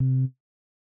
Rhodes.wav